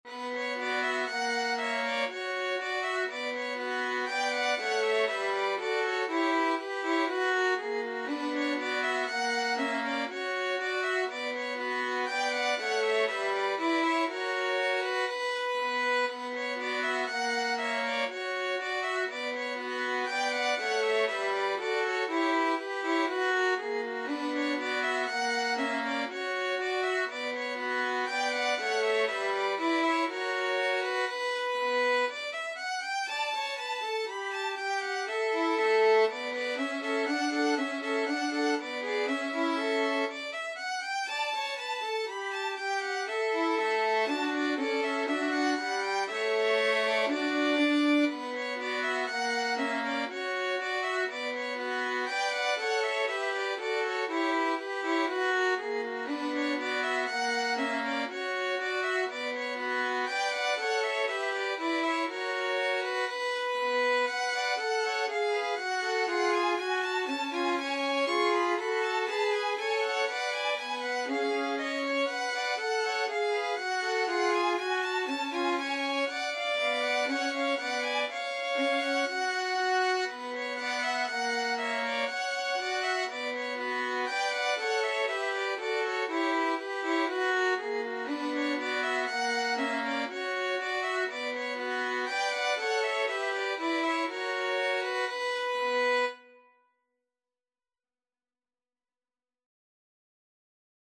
2/2 (View more 2/2 Music)
~ = 100 Allegro (View more music marked Allegro)
D major (Sounding Pitch) (View more D major Music for Violin Trio )
Violin Trio  (View more Intermediate Violin Trio Music)
Classical (View more Classical Violin Trio Music)
bach_gavotte_bwv822_3VLN.mp3